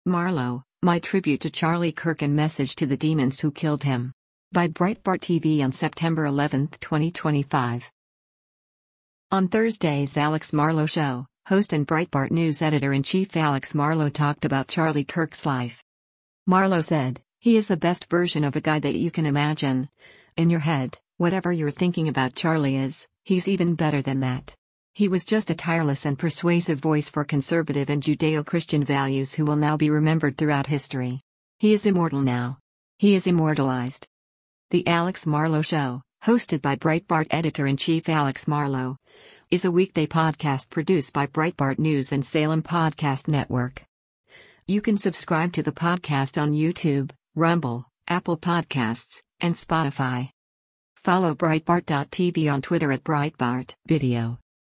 On Thursday’s “Alex Marlow Show,” host and Breitbart News Editor-in-Chief Alex Marlow talked about Charlie Kirk’s life.